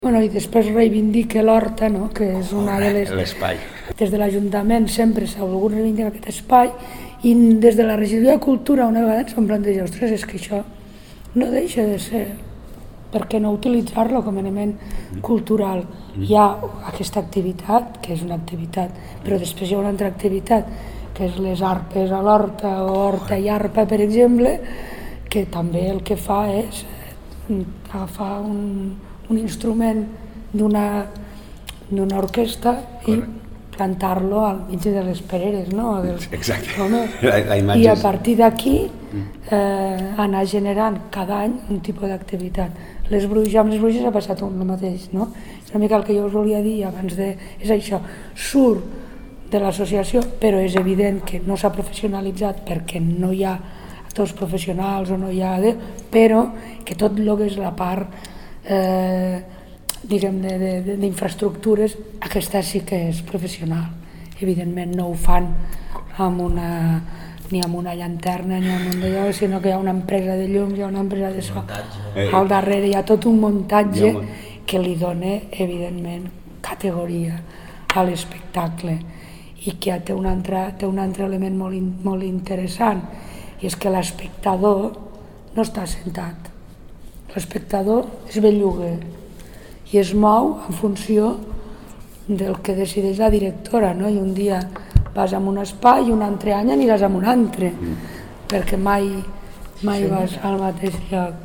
tall-de-veu-de-la-tinent-dalcalde-montse-parra-sobre-lescenificacio-de-les-bruixes-del-pont-del-boc